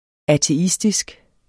Udtale [ ateˈisdisg ]